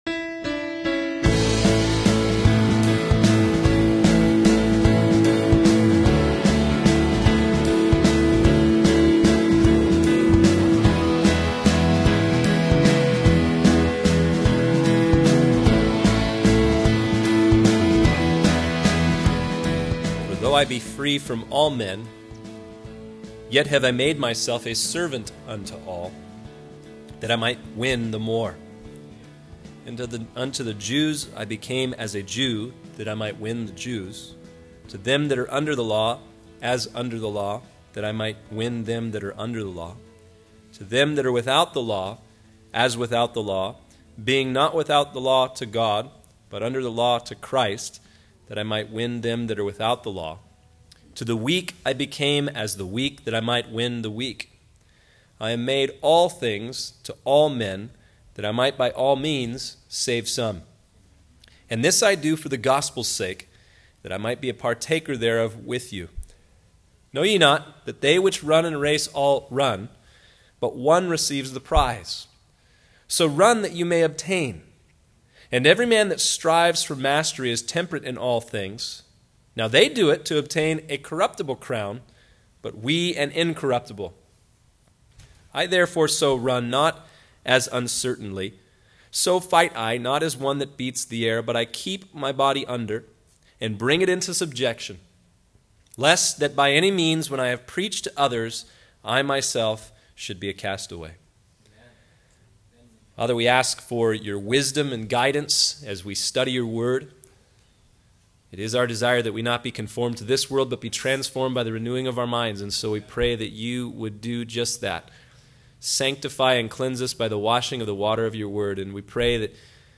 In this sermon, the speaker discusses the analogy of athletes preparing for the ancient games in Corinth. He emphasizes the rigorous training and discipline that these athletes underwent in order to compete and win the prize. The speaker then applies this analogy to the Christian life, highlighting the need for believers to be disciplined and prepared in their faith.